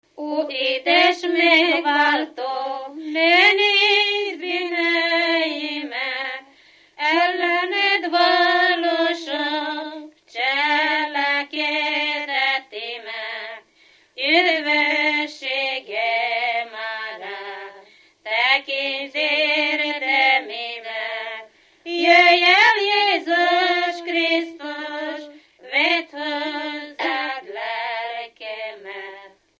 Dunántúl - Verőce vm. - Lacháza
Stílus: 4. Sirató stílusú dallamok
Kadencia: 4 (2) 2 1